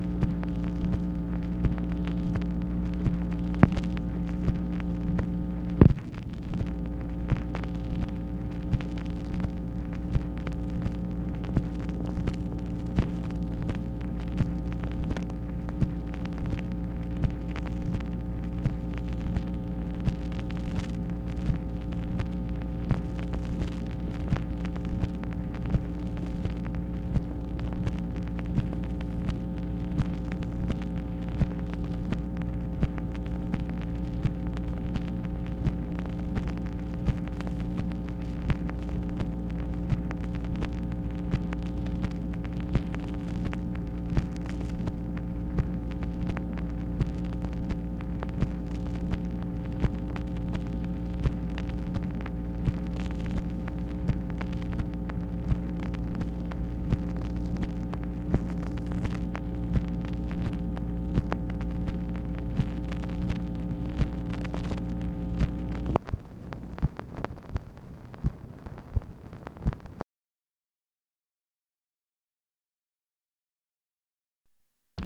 MACHINE NOISE, February 15, 1967
Secret White House Tapes | Lyndon B. Johnson Presidency